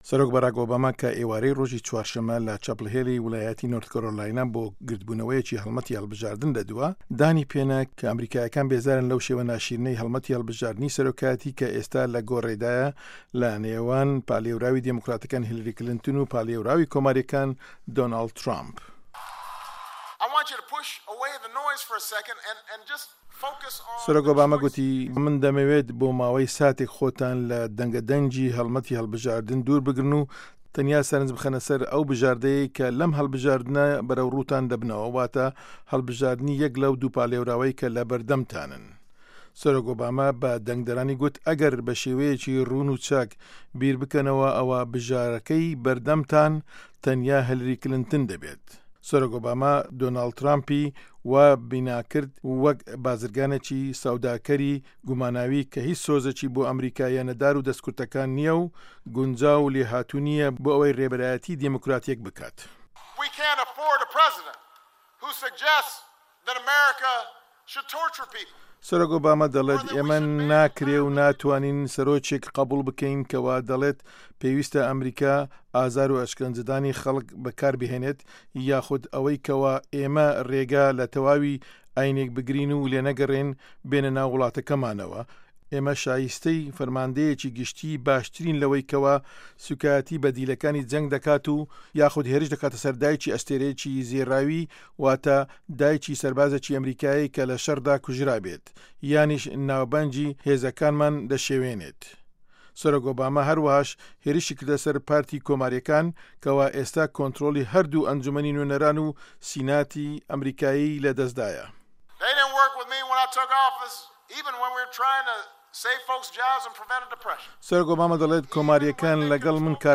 دەقی ڕاپـۆرتەکە